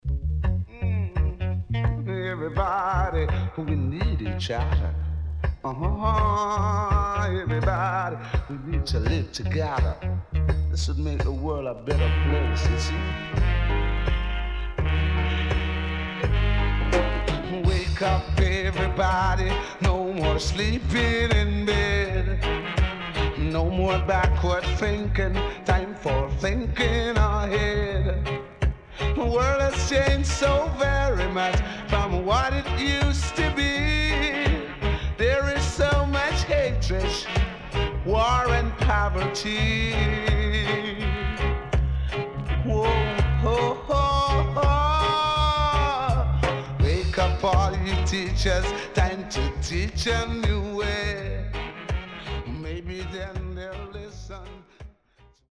SIDE A:プレス起因により少しノイズ入りますが良好です。